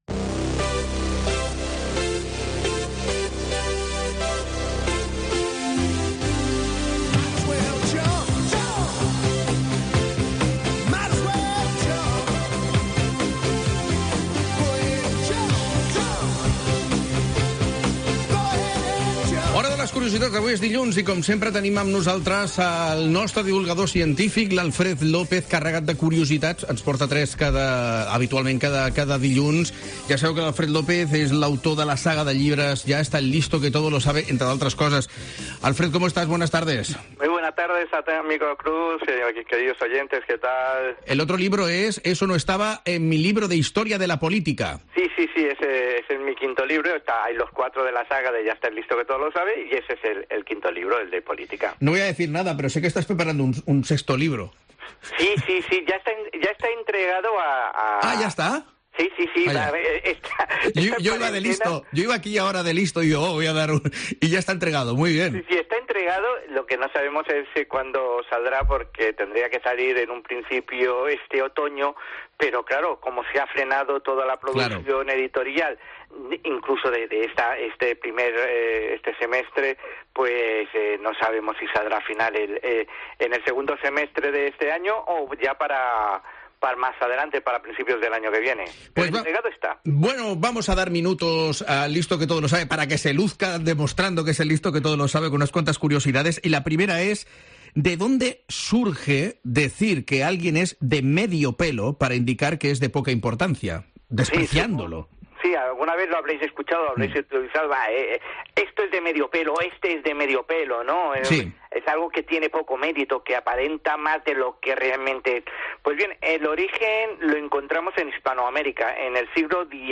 Cada Lunes nos explica tres de estas curiosidades en La Linterna Catalunya, habitualmente en estudio, hoy, confinado telefonicamente.